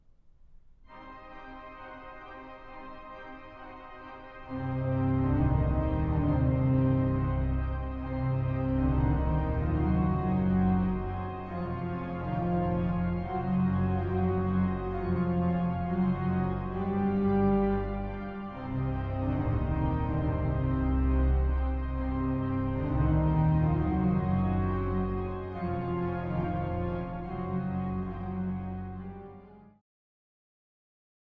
Ladegast-Sauer-Eule-Orgel in der Nikolaikirche zu Leipzig